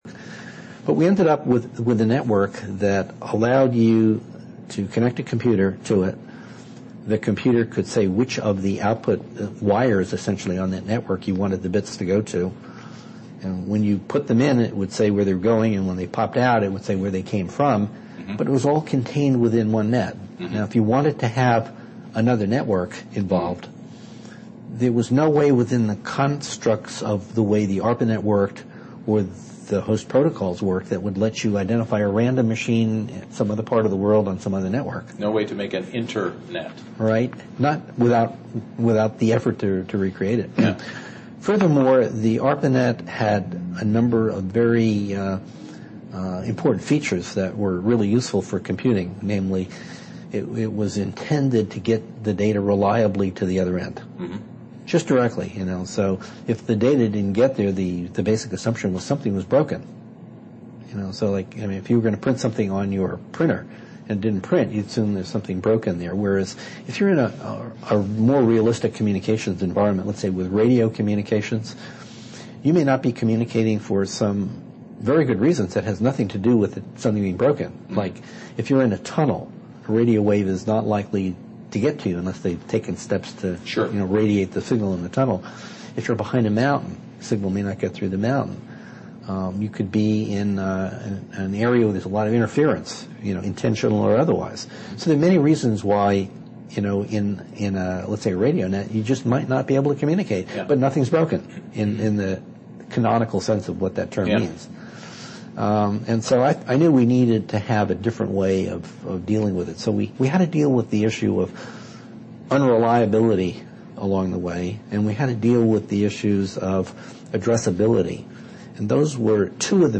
Interview of Bob Kahn